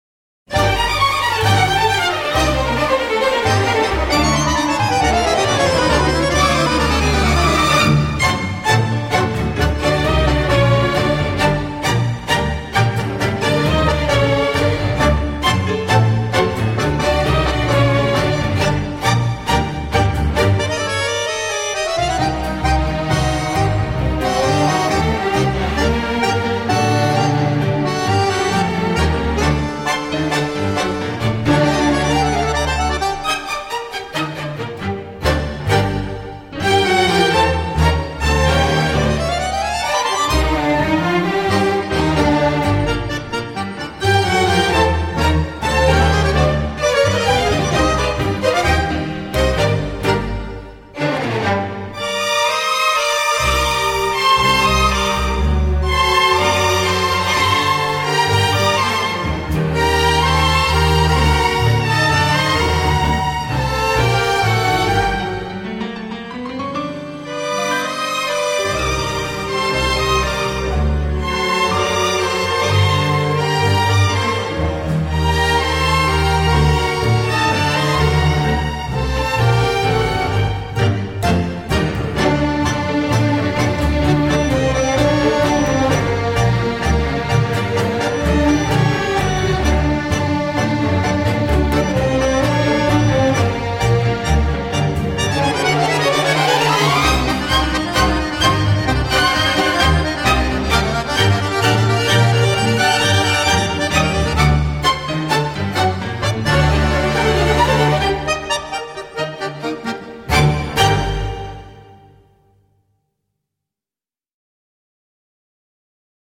【纯乐探戈】
在跳探戈的时候节奏的偶尔停顿让舞者更加兴奋。
曲子开头通常伴有许多手风琴，这使得音乐在某种程度上稍具古风。